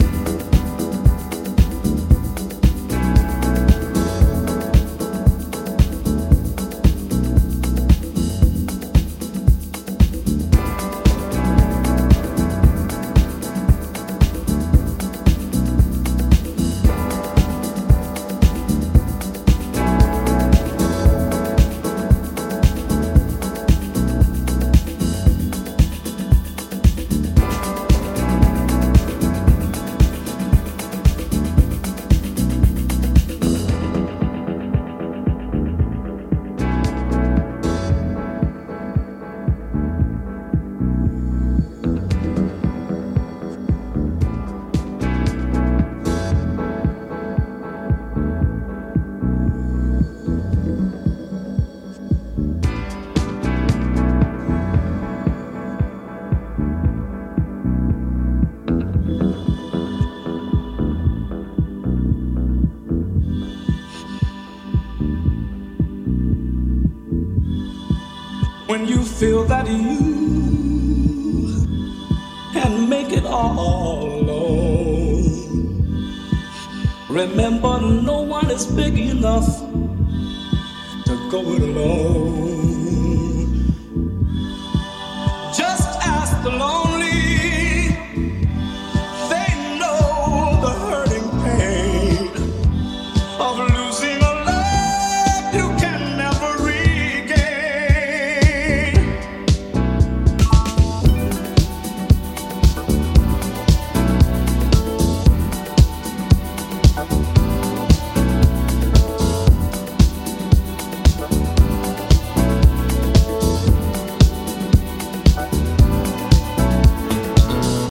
a soulful, goosebumps inducing beauty